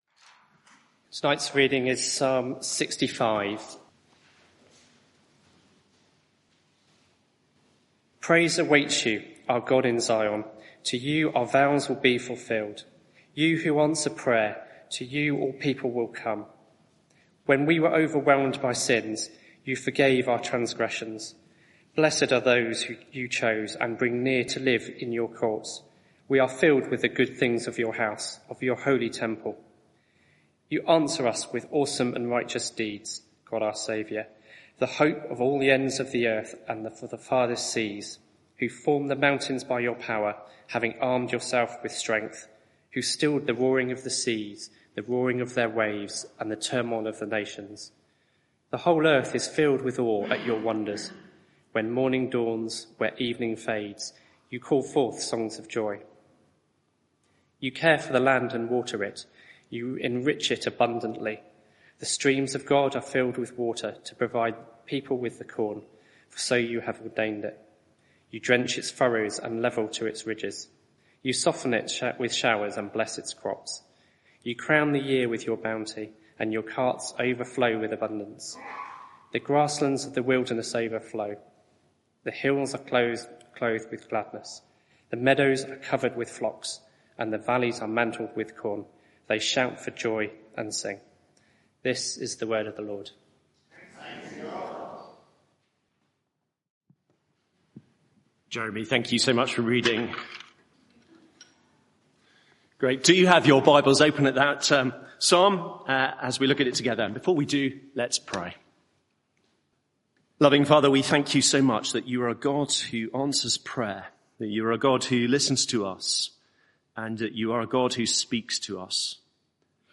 Media for 6:30pm Service on Sun 04th Aug 2024 18:30 Speaker
Passage: Psalm 65 Series: Telling God How I Feel Theme: When I’m grateful Sermon (audio)